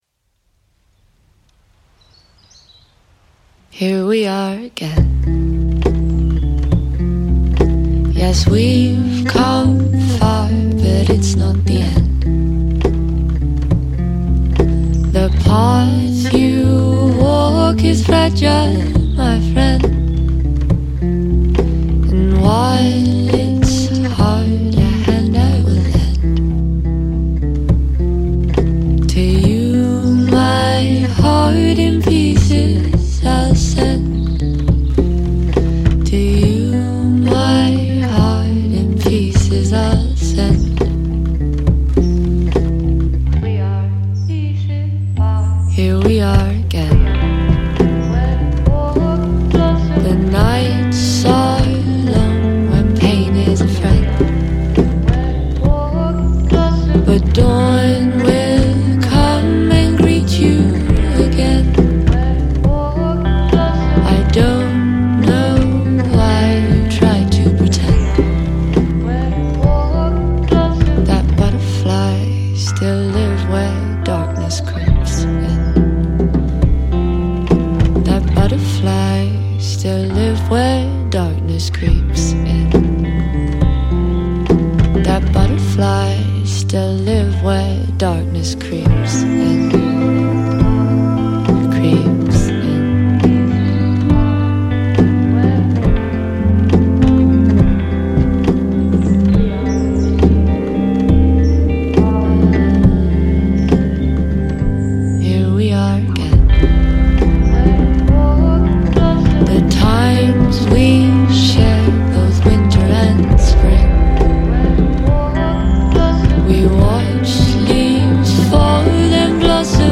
UK Indie folk